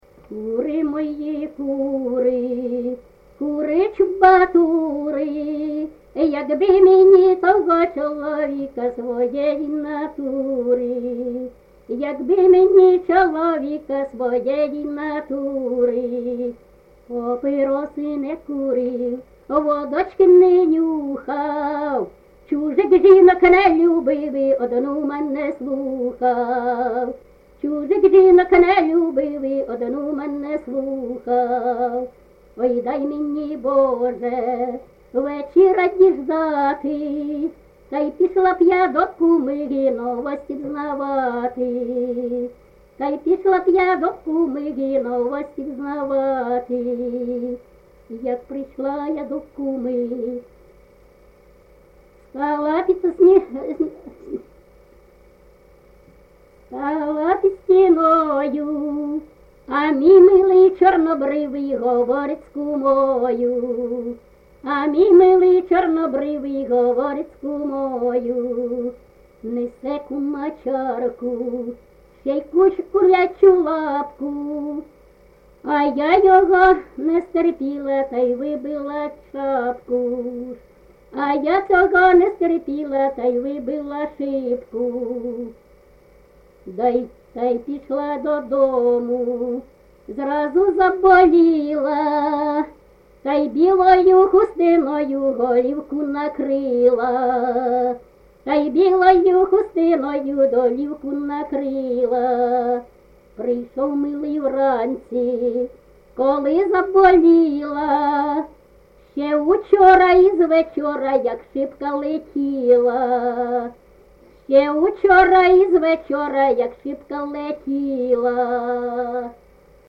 ЖанрЖартівливі
Місце записус-ще Михайлівське, Сумський район, Сумська обл., Україна, Слобожанщина